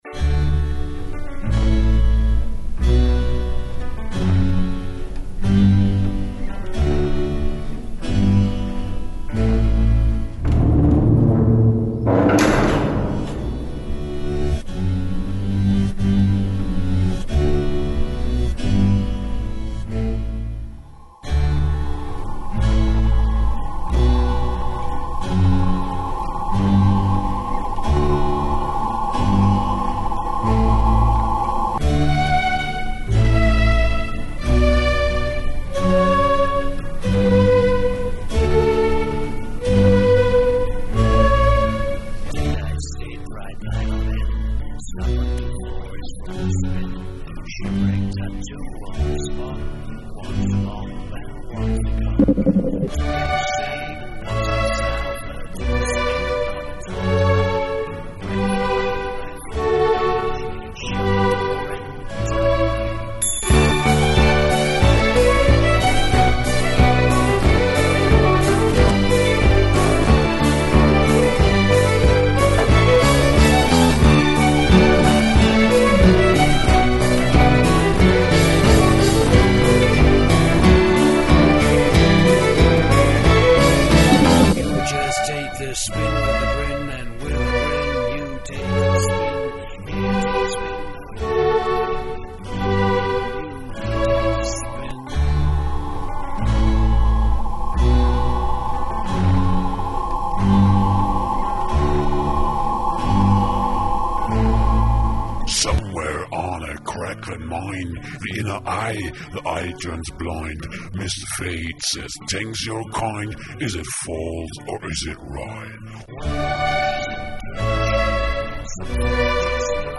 orchestra version
all still a bit unfinished/sketchy